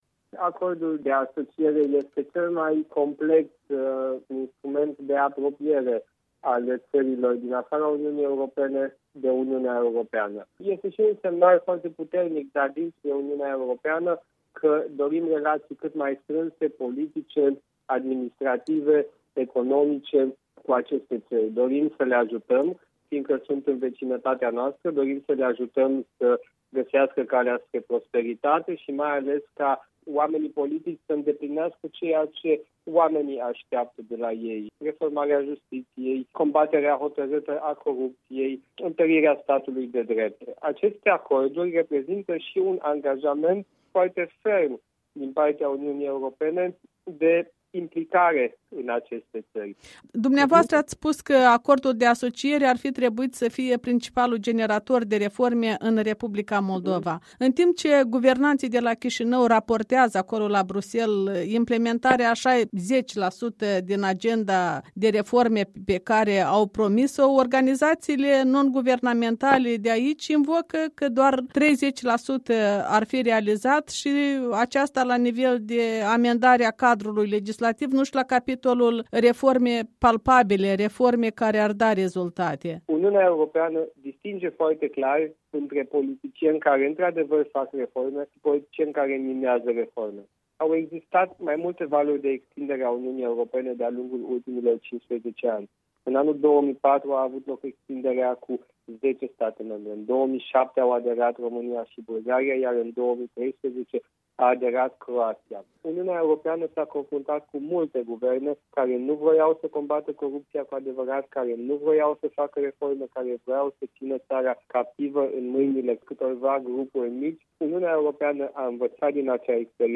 Un interviu cu europarlamentarul român de la Bruxelles.